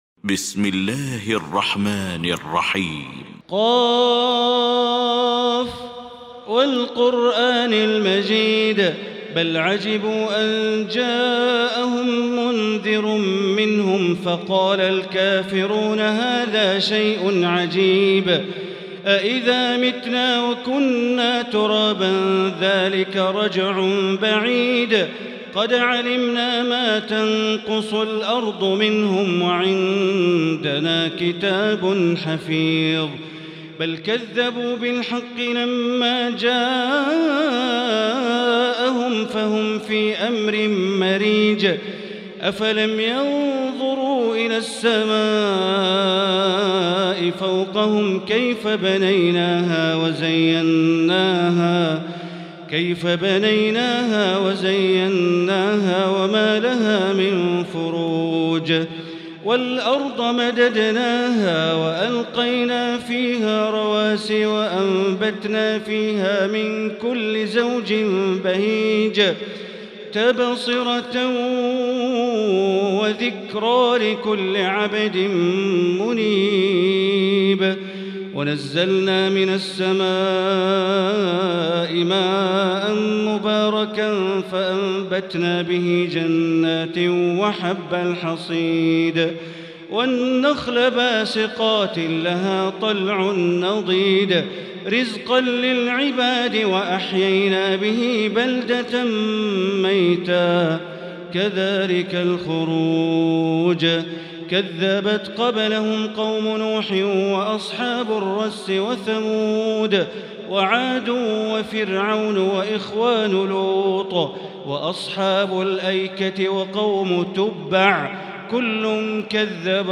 المكان: المسجد الحرام الشيخ: معالي الشيخ أ.د. بندر بليلة معالي الشيخ أ.د. بندر بليلة ق